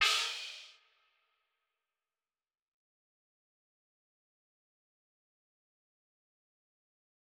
Crashes & Cymbals
DMV3_Crash 8.wav